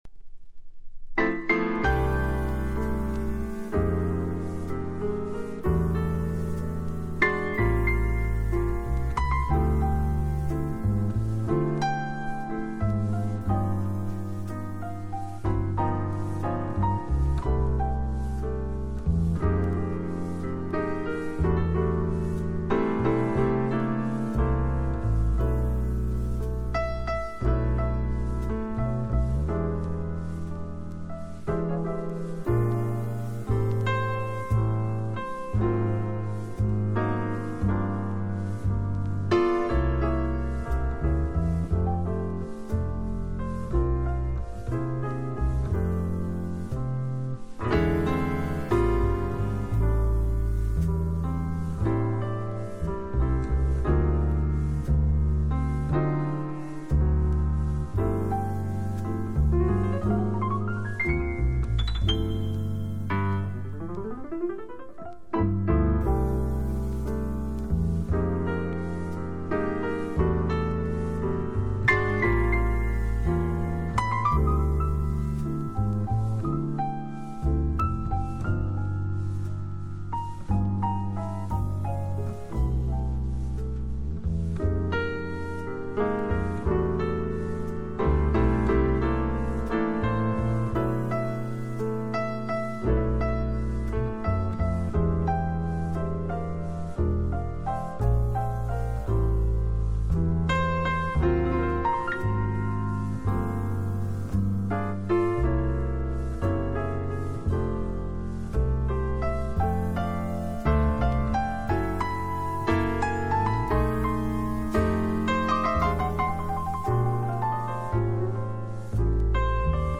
トリオもの好内容盤
（プレス・小傷によりチリ、プチ音、サーノイズある曲あり）※曲名を…